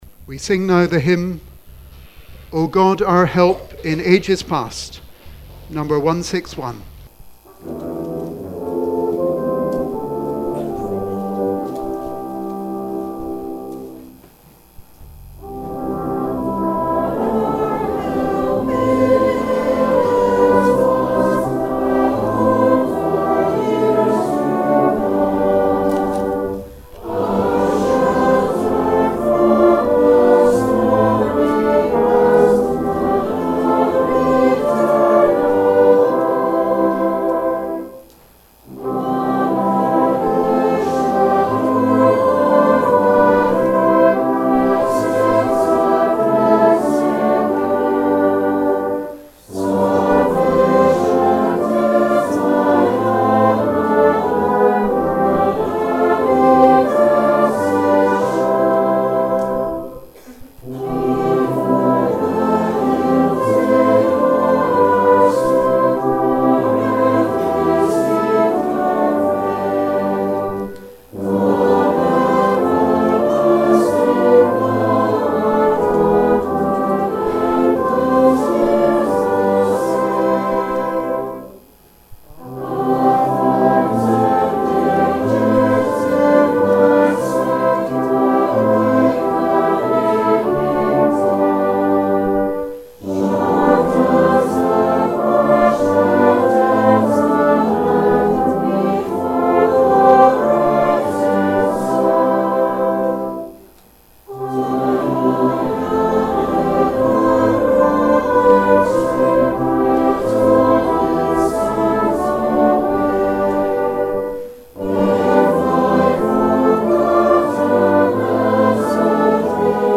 Our praise continued with '